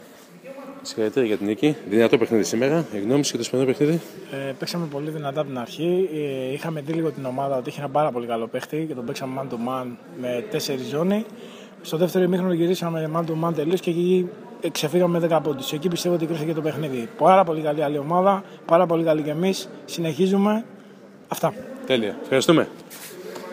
Inteview